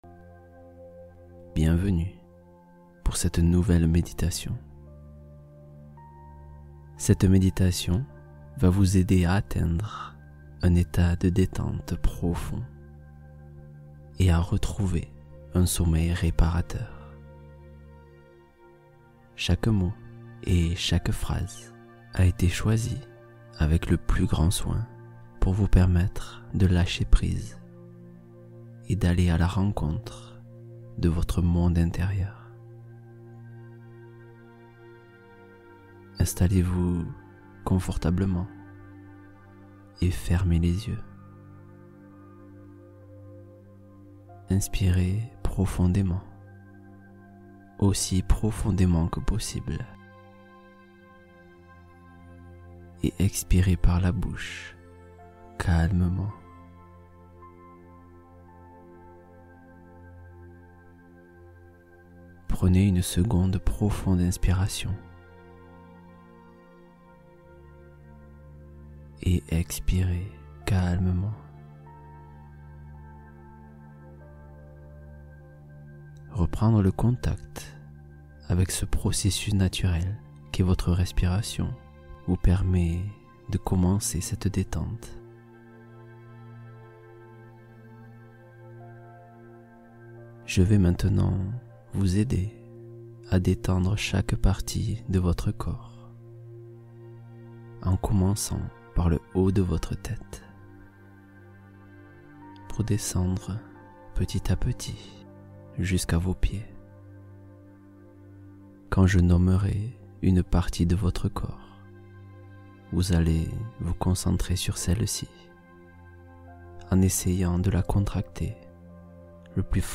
Mémorisation soutenue — Hypnose pour accompagner l’apprentissage